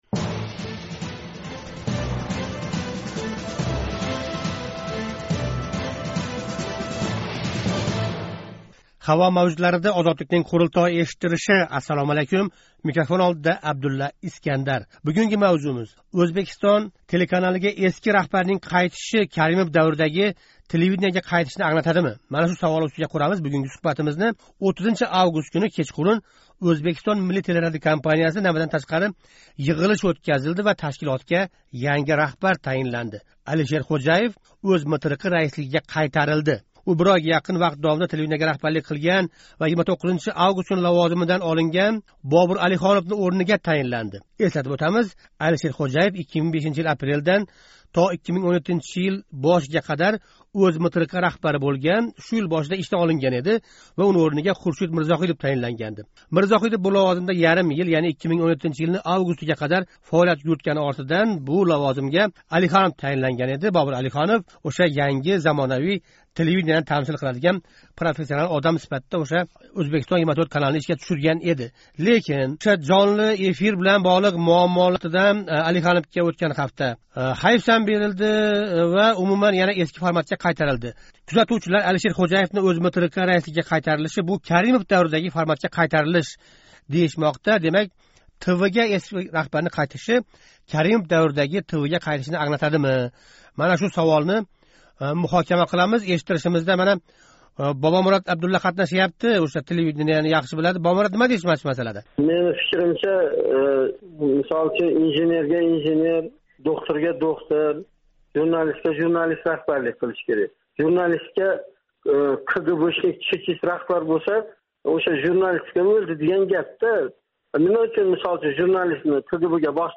Озодликнинг Қурултой эшиттиришига йиғилган ўзбек журналистлари ўзбек журналистикасида бошланган “муз эриши” жараëни тўхтаб¸ яна “цензура” даври бошланмоқдами, деган хавотирни билдиришди. Бу хавотирга МТРКдаги кейинги ўзгаришлар сабаб бўлгани айтилди.